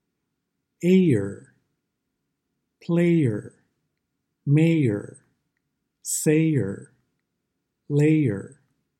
Lesson 5 – “R” vowels /ɝ/, /ɚ/, /ɑr/, /ɜr/, /ɪr/, /ɔr/ – American English Pronunciation